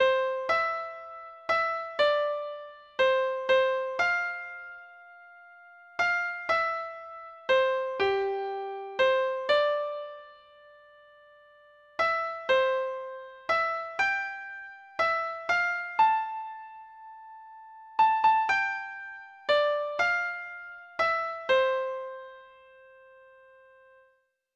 Treble Clef Instrument version
Folk Songs
Traditional Music of unknown author.